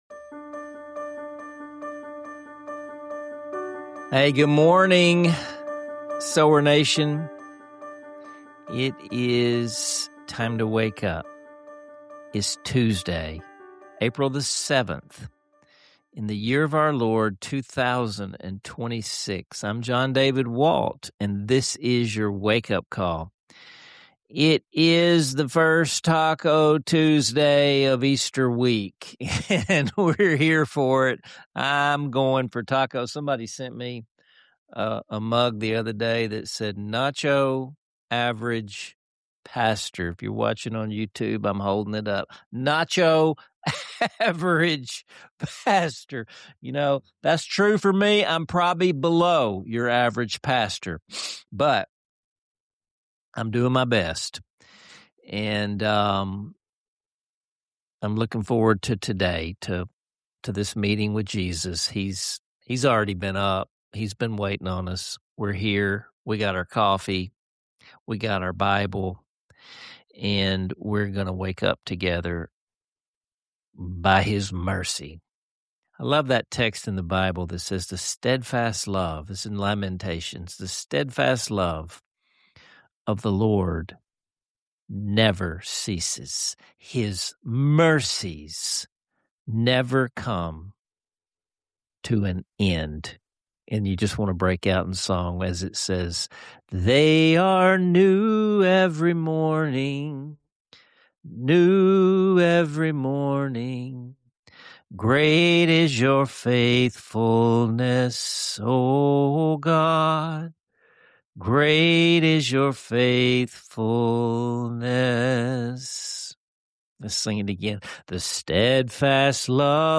Listen in for thoughtful questions, soul-stirring worship, and a warm family moment that reminds us all — faithfulness is always in season.